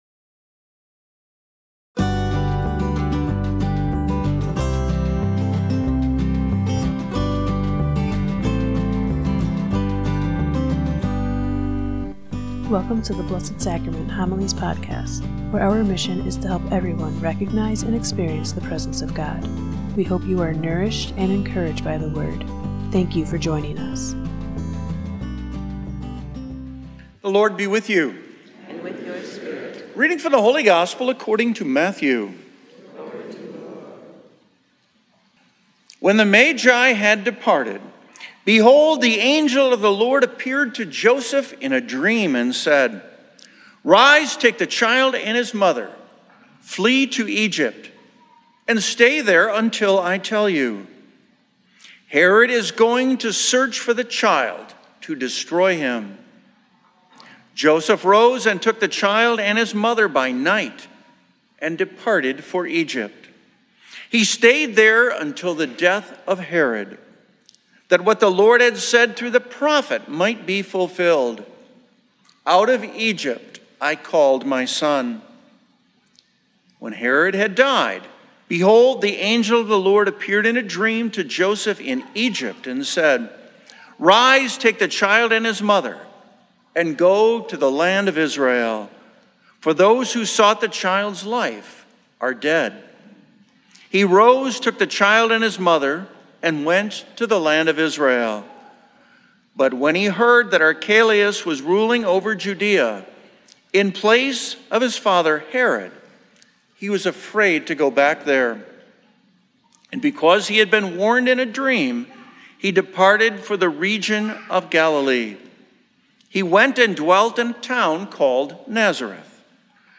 Blessed Sacrament Parish Community Homilies
With humor and honesty, this homily acknowledges what we all know to be true: every family is made up of different characters, virtues, struggles, and personalities.